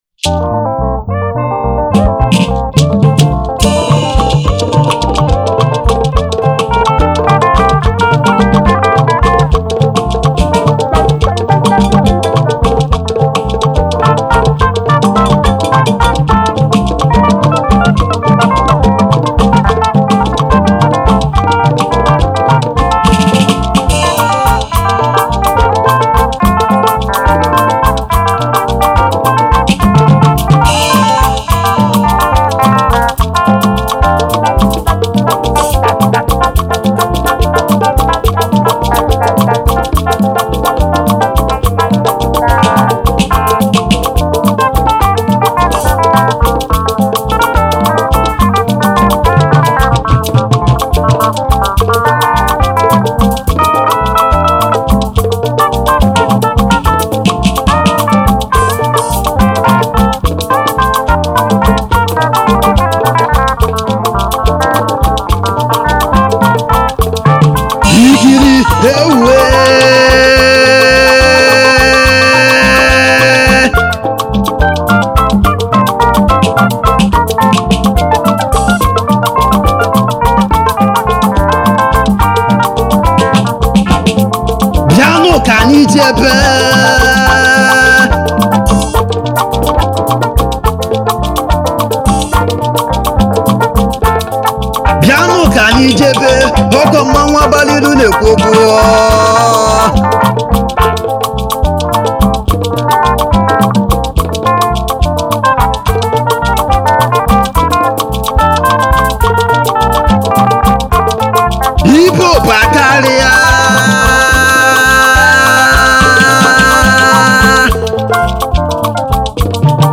Highlife Traditional